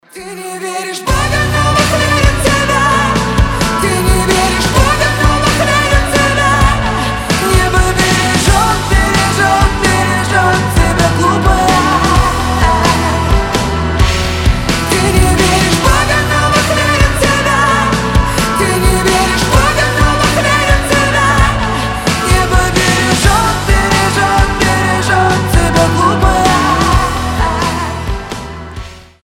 • Качество: 320, Stereo
сильные
красивый вокал